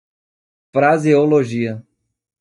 Pronunciato come (IPA) /fɾa.ze.o.loˈʒi.ɐ/